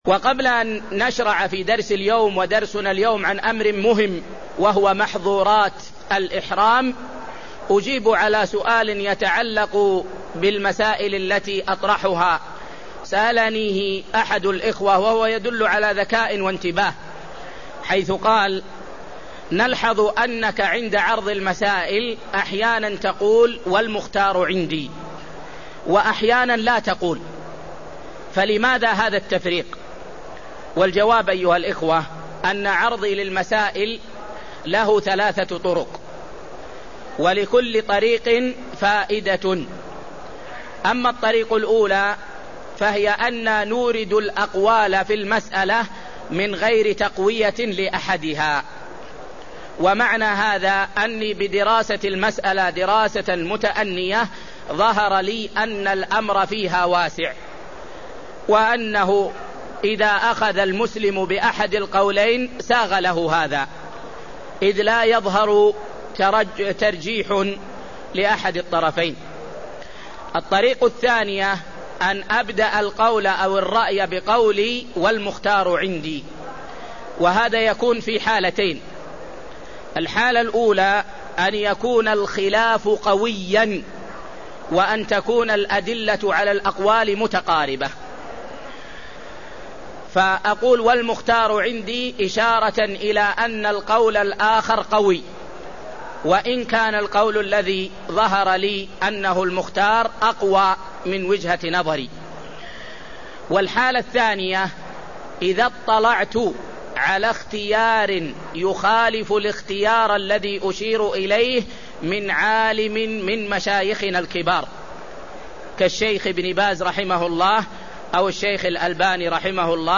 تاريخ النشر ١٨ ذو القعدة ١٤٢٦ هـ المكان: المسجد النبوي الشيخ